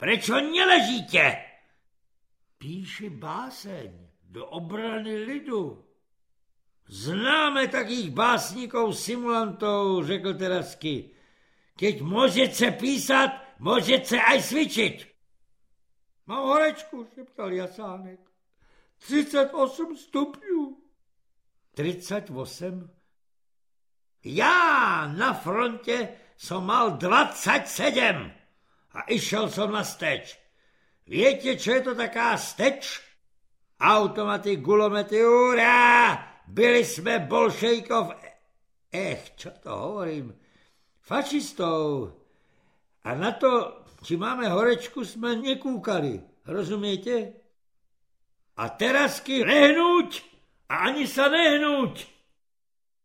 Černí baroni audiokniha
Ukázka z knihy
Satirický román Miloslava Švandrlíka v nezapomenutelném podání jeho hlavního filmového protagonisty, majora Terazkyho - Pavla Landovského.
• InterpretPavel Landovský